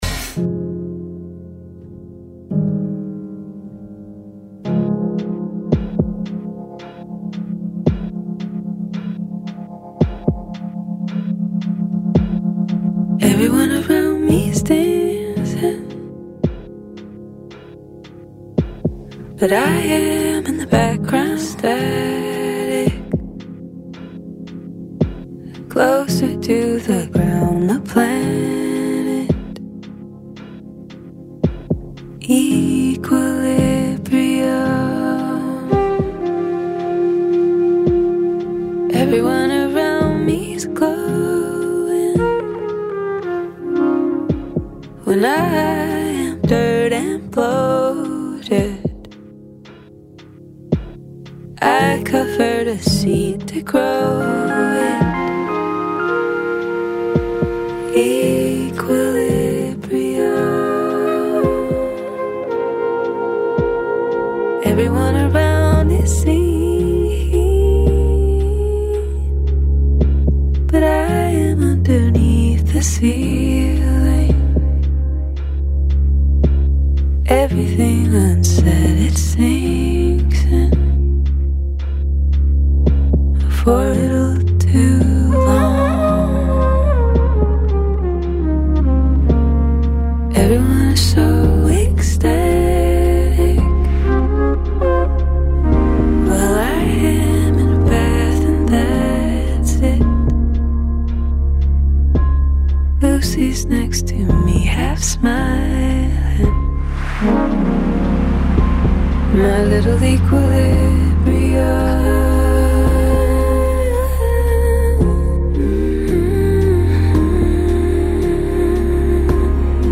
La musica come unico raccordo capace di intrecciare più racconti.